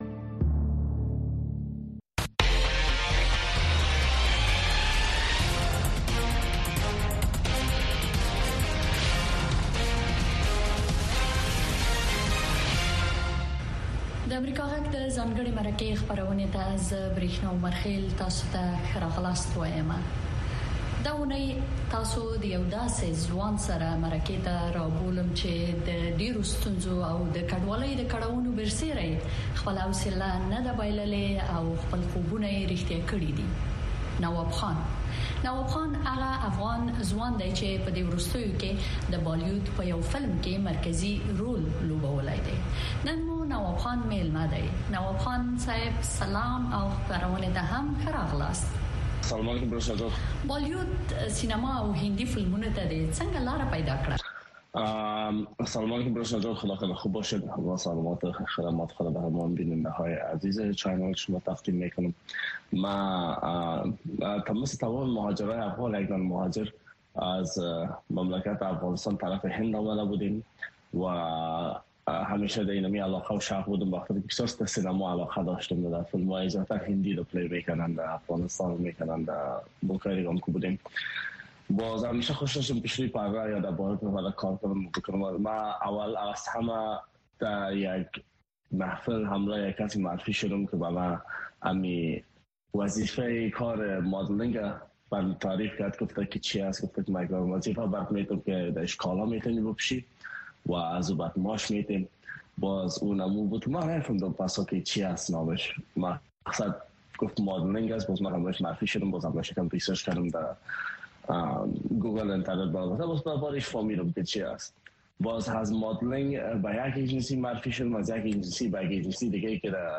ځانګړې مرکه
د افغانستان او نړۍ د تودو موضوعګانو په هکله د مسولینو، مقاماتو، کارپوهانو او څیړونکو سره ځانګړې مرکې هره چهارشنبه د ماښام ۶:۰۰ بجو څخه تر ۶:۳۰ بجو دقیقو پورې د امریکاغږ په سپوږمکۍ او ډیجیټلي خپرونو کې وګورئ او واورئ.